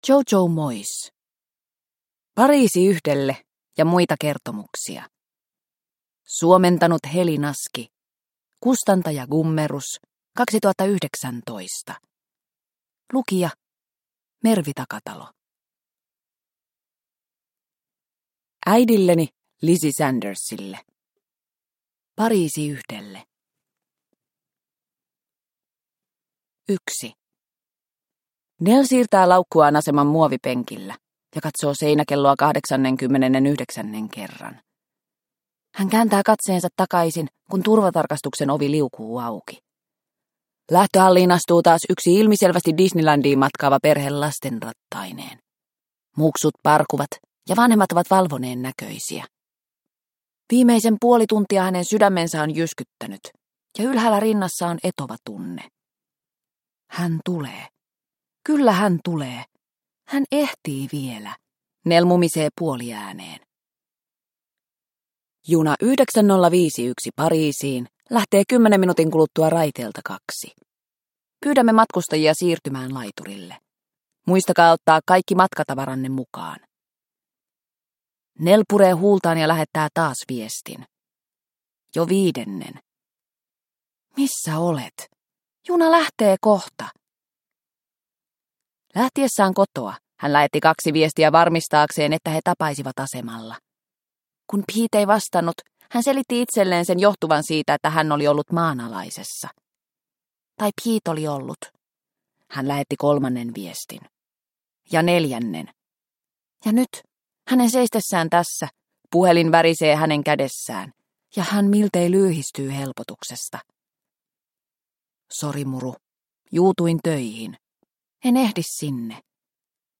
Pariisi yhdelle ja muita kertomuksia – Ljudbok – Laddas ner